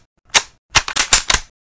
mosin_unjam.ogg